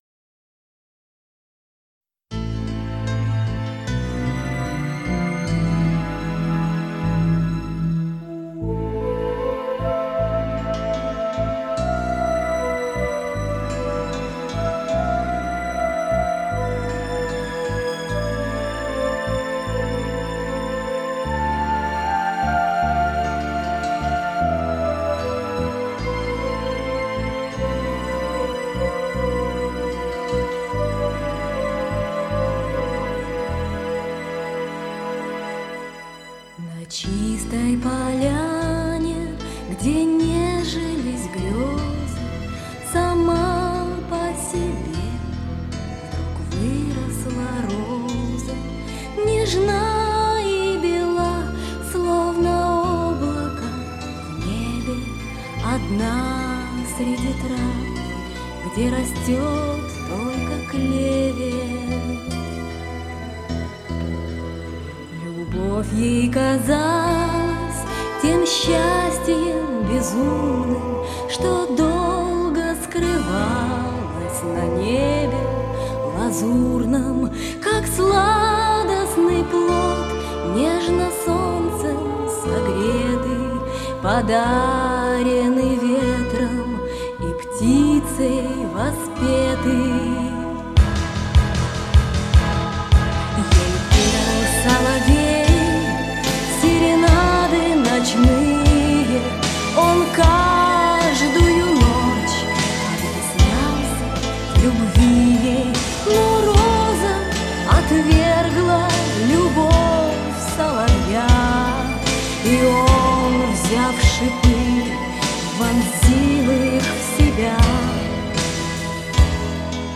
автор муз.и слов, исполнитель